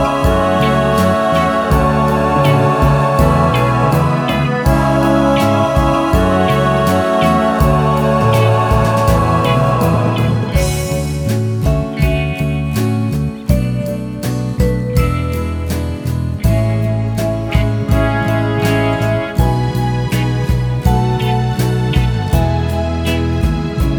For Male Duet Pop (1960s) 3:13 Buy £1.50